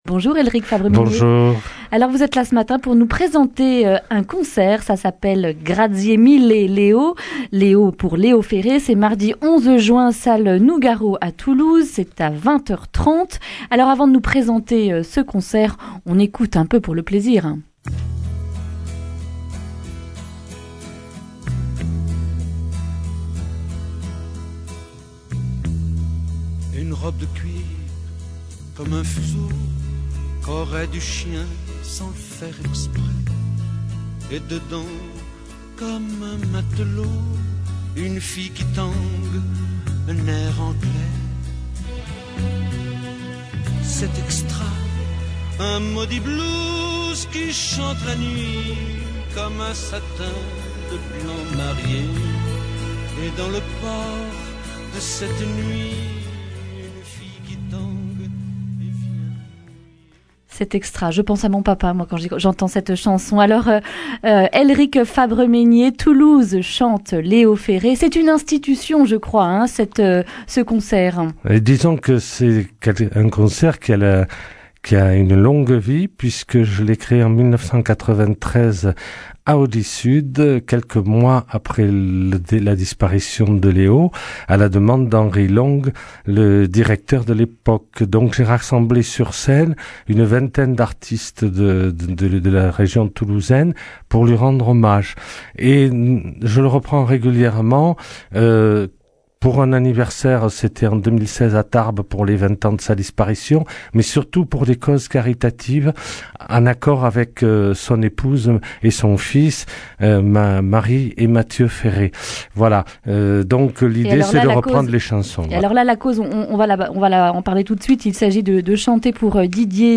vendredi 7 juin 2019 Le grand entretien Durée 10 min